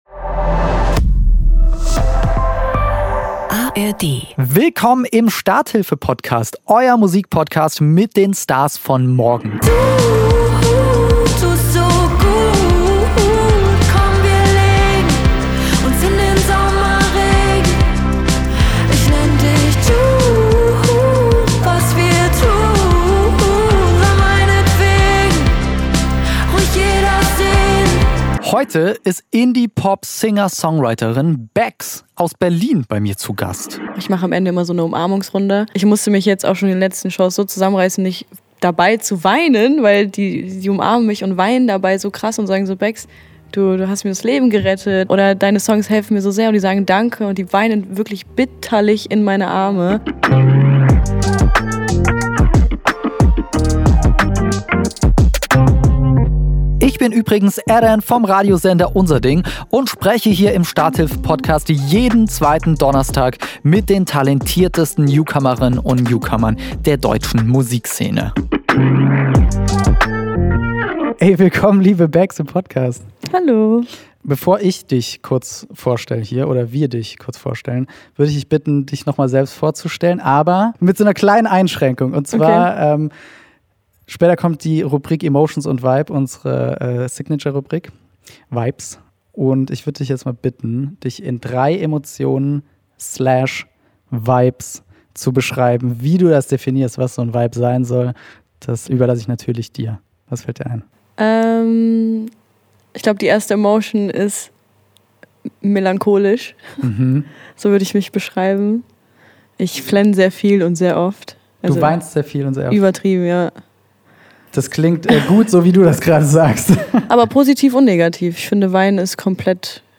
Indie-Pop Sängerin